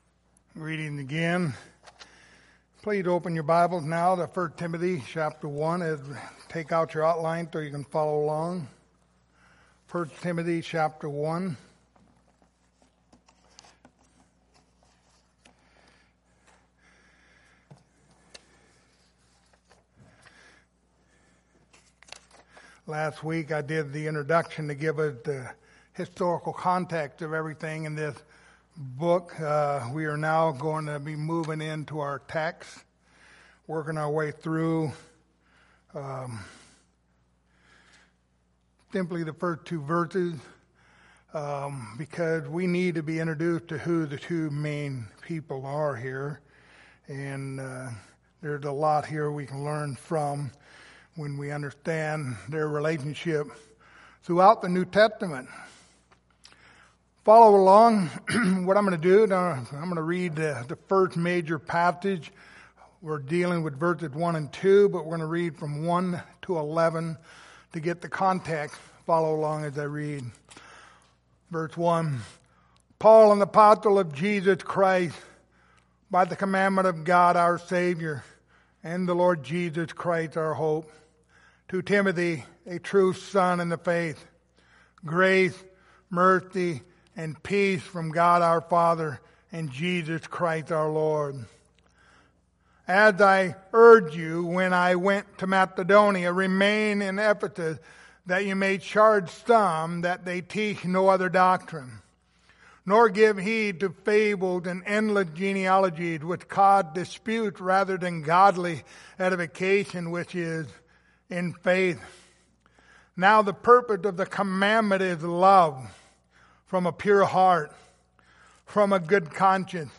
Passage: 1 Timothy 1:1-2 Service Type: Sunday Morning